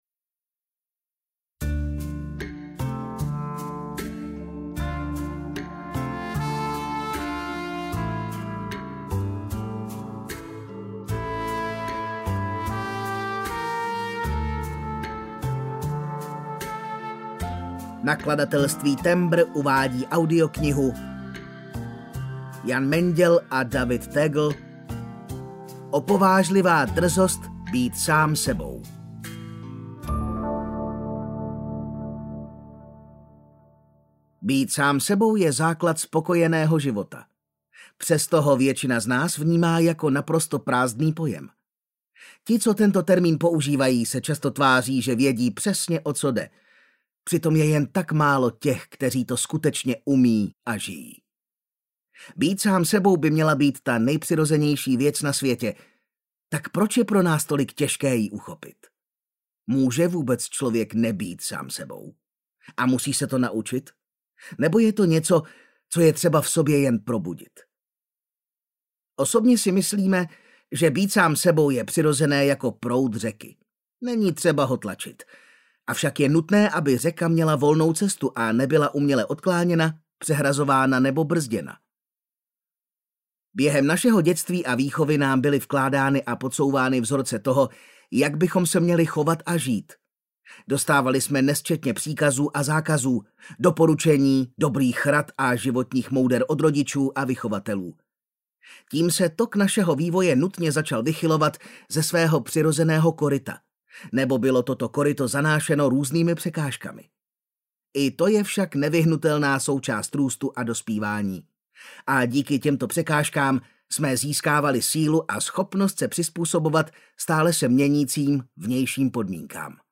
Opovážlivá drzost být sám sebou audiokniha
Ukázka z knihy